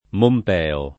[ momp $ o ]